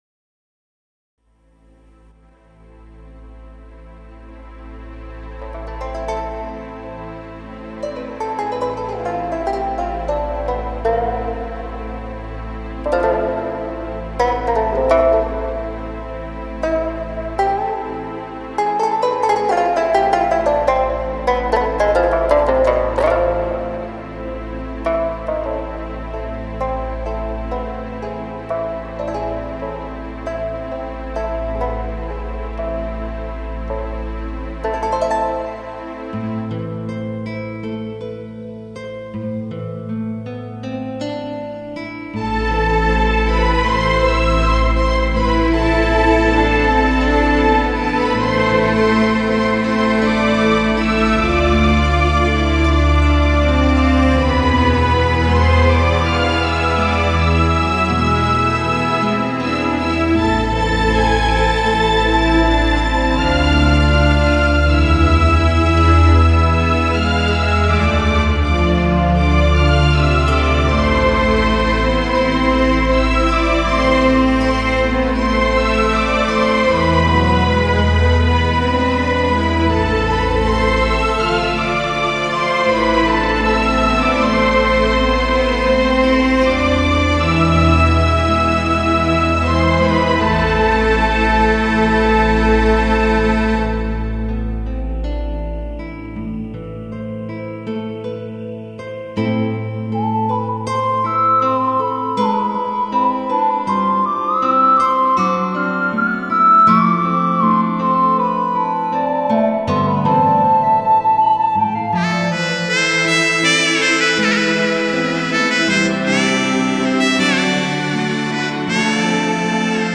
民族音乐 >> 新疆民族音乐